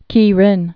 (kērĭn)